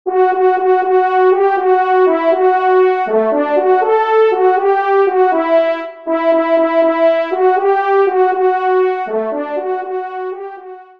Fanfare de personnalité